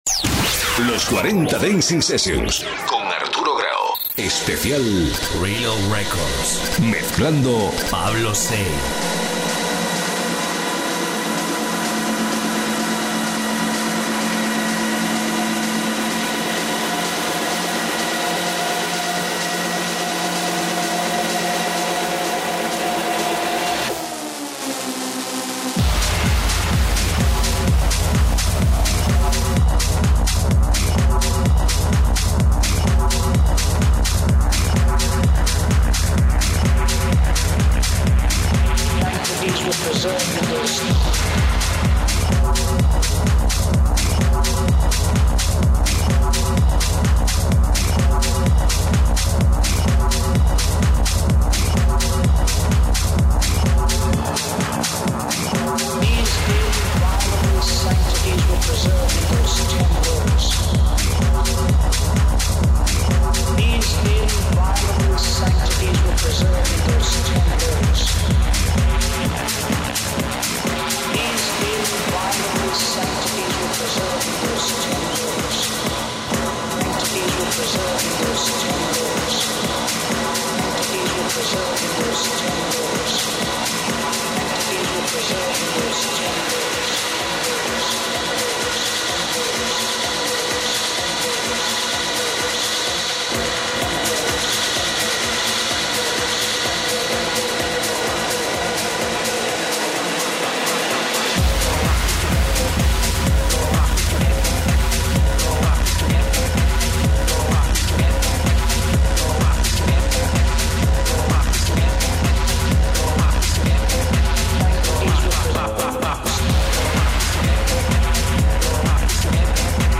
Esto es Rock! es un espacio musical dedicado al mundo del Rock, en el que tienen lugar de privilegio tendencias que van del Rock Sinfónico al Metal Progresivo e instrumental, el Thrash, el Black o el Death Metal , pasando por el Rock Clásico o el Hard Melódico.